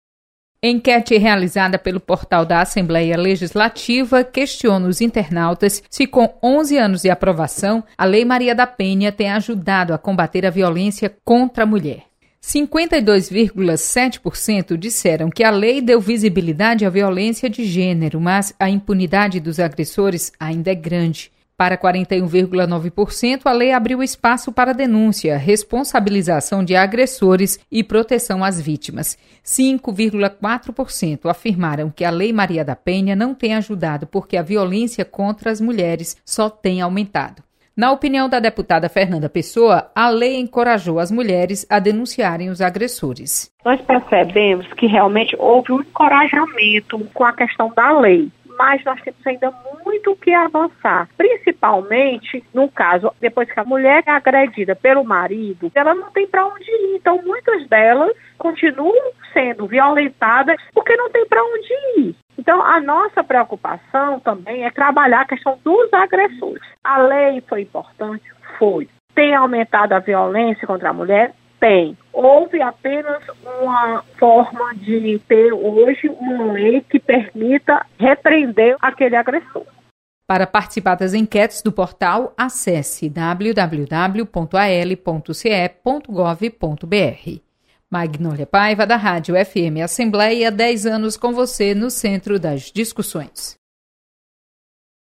Enquete